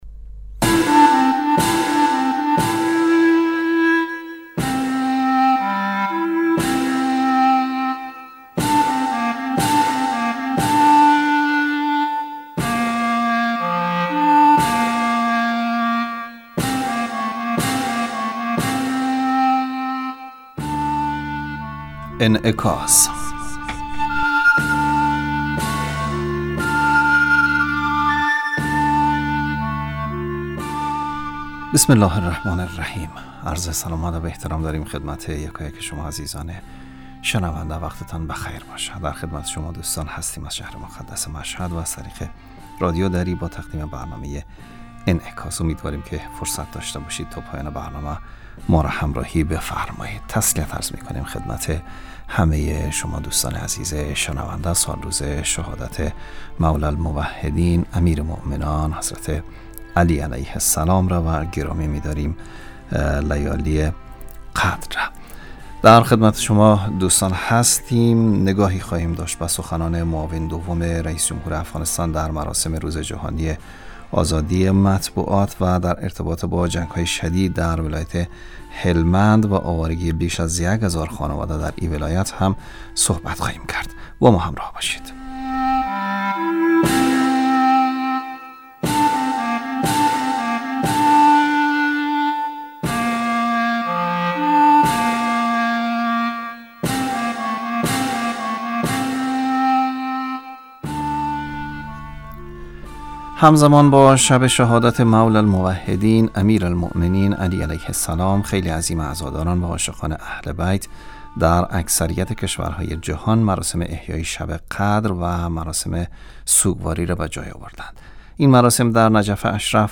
برنامه انعکاس به مدت 30 دقیقه هر روز در ساعت 12:05 ظهر (به وقت افغانستان) بصورت زنده پخش می شود.